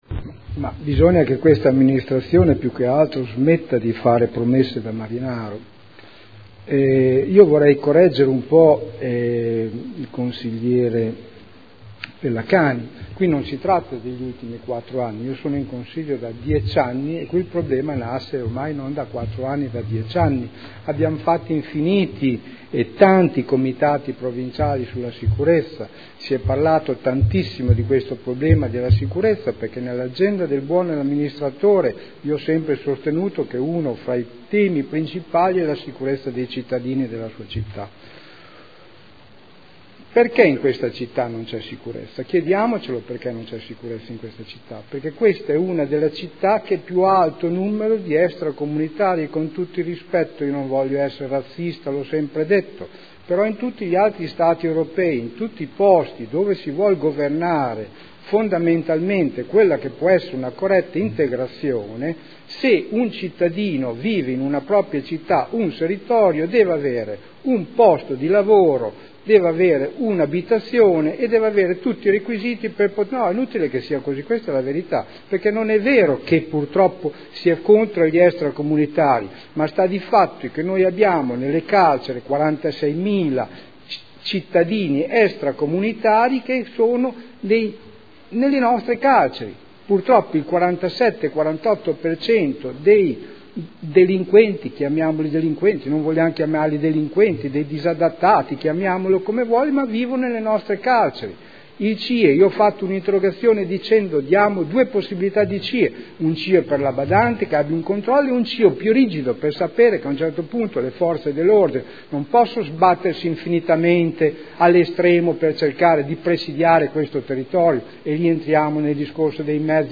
Seduta del 19/09/2013. Dibattito su interrogazione della consigliera Maienza (P.D.) avente per oggetto: “Sicurezza.